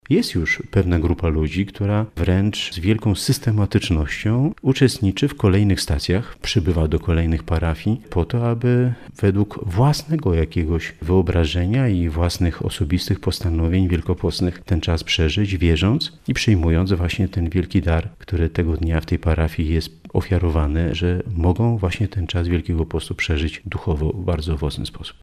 Mówi bp Marek Solarczyk